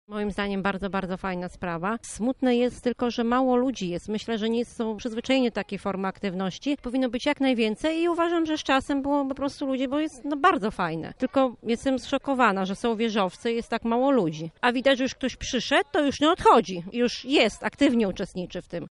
Każdy, kto tutaj przyszedł, został z nami już do samego końca – mówi jedna z uczestniczek warsztatów